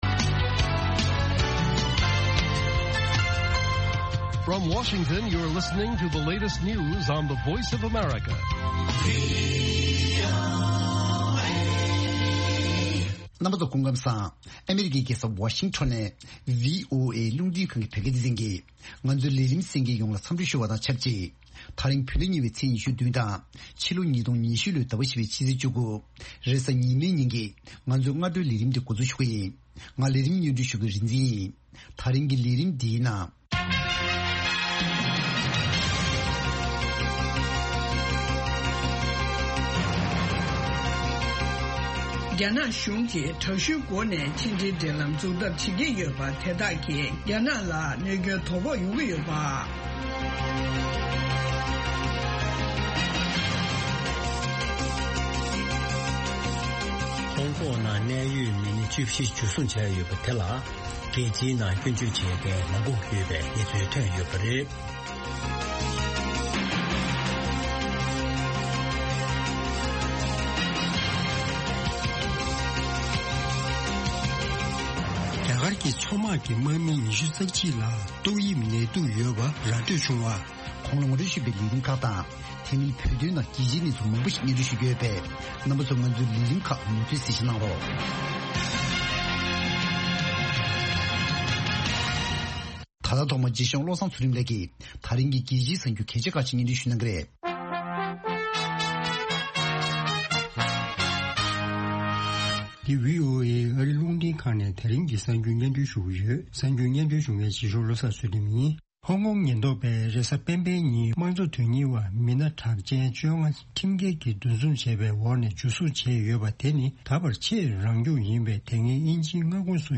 Afternoon Show Broadcast daily at 12:00 Noon Tibet time, the Lunchtime Show presents a regional and world news update, followed by a compilation of the best correspondent reports and feature stories from the last two shows. An excellent program for catching up on the latest news and hearing reports and programs you may have missed in the morning or the previous night.